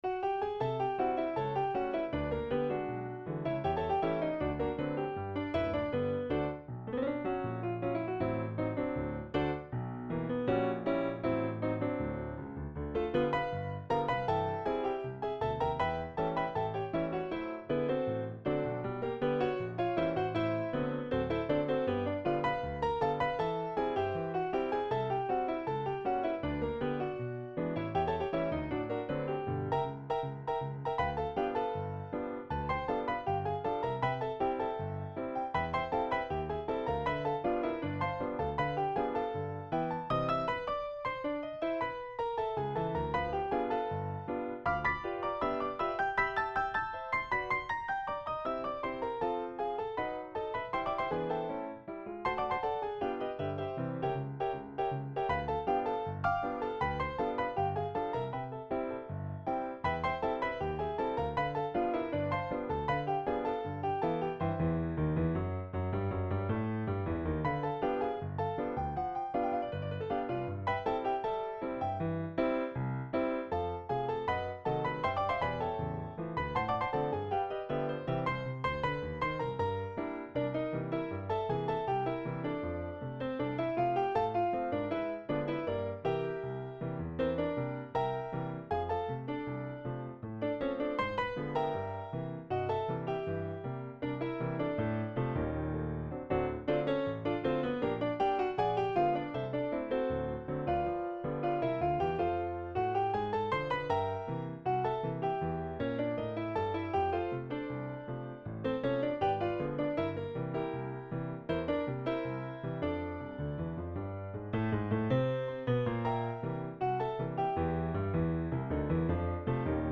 Stride piano